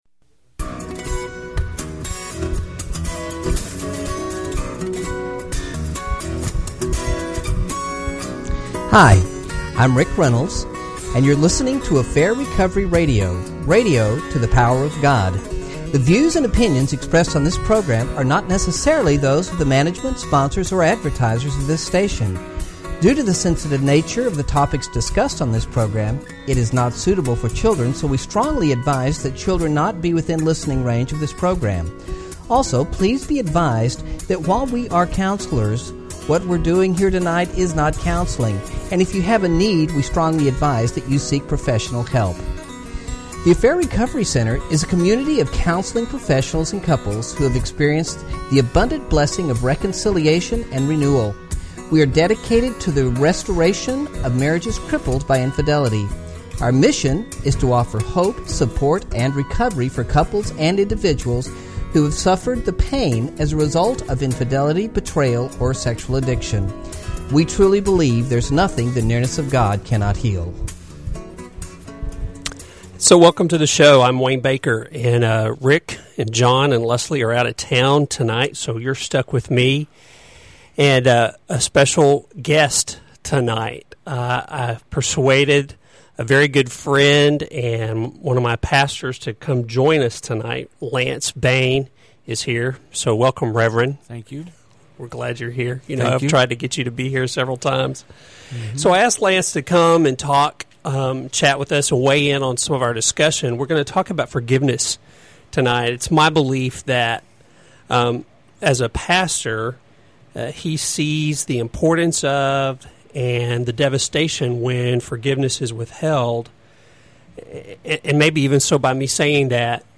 Two callers also join the show with their own questions and comments about forgiveness.